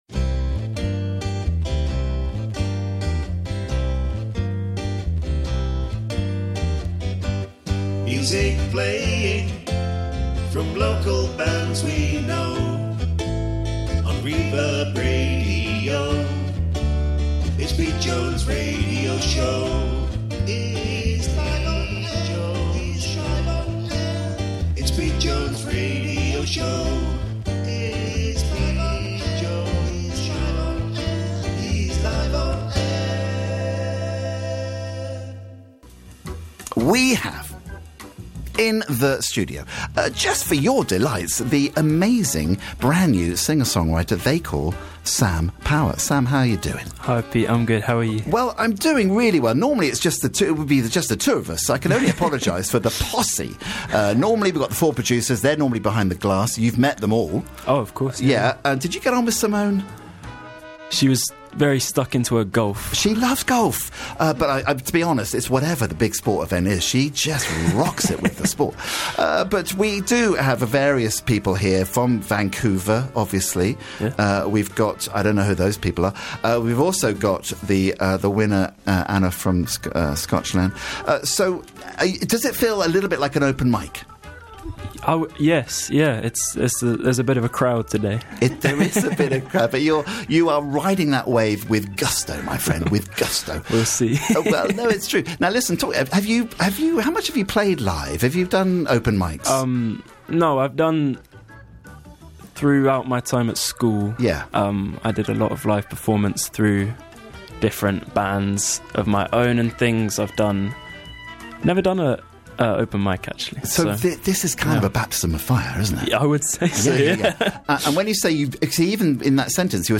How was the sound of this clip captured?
3 Live tracks played out: 1) Won't you wait 2) That Much 3) Crazy